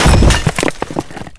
pd_bricks1.wav